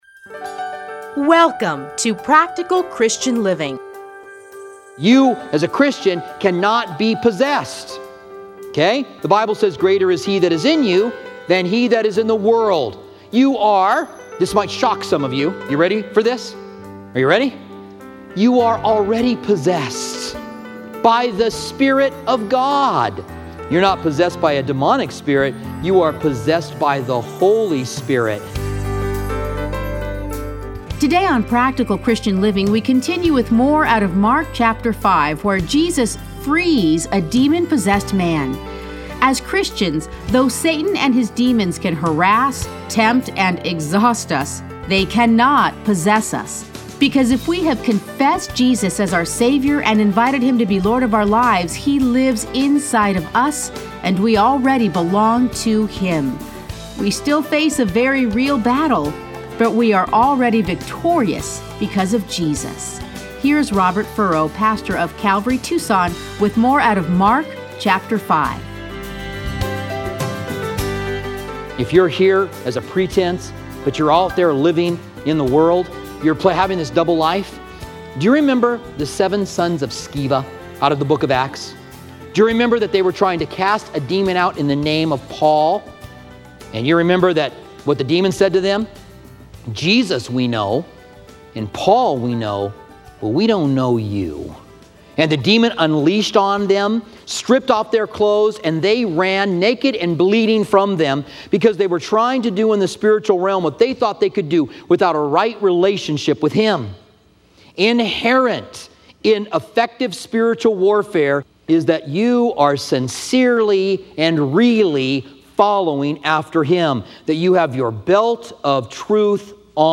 Listen to a teaching from Mark 5:1-20.